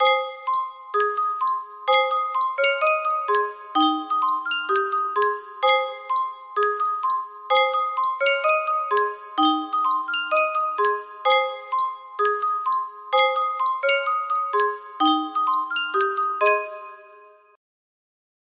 Yunsheng Music Box - Rain Rain Go Away 1026 music box melody
Full range 60